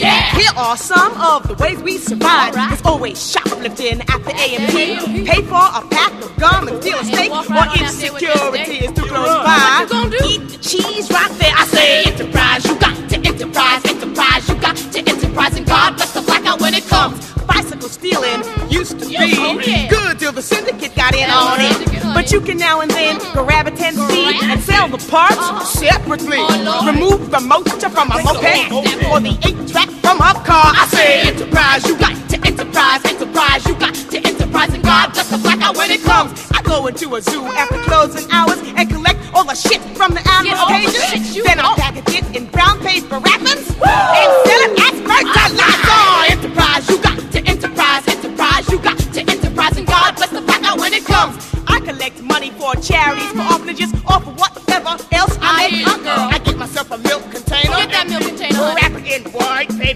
EASY LISTENING / OST / COUNTRY / COUNTRY ROCK